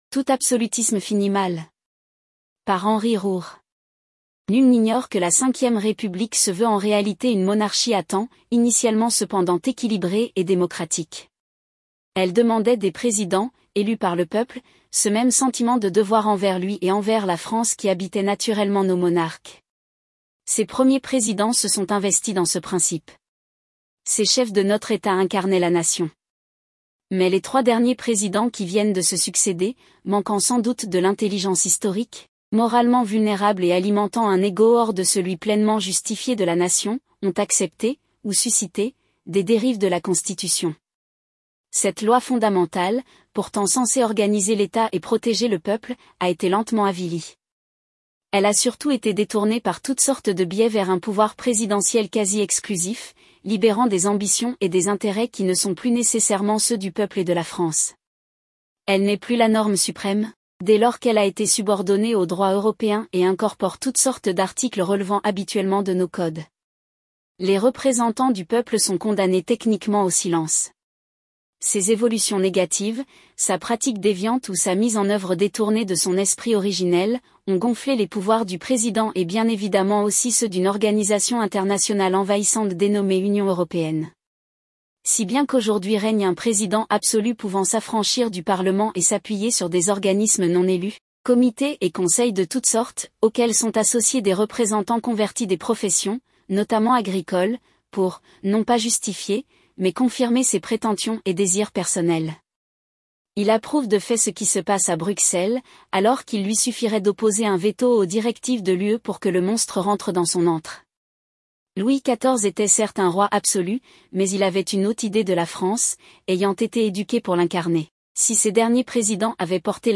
par | 1 Fév 2024 | Tribunes libres, TTS